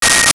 Sci Fi Beep 11
Sci Fi Beep 11.wav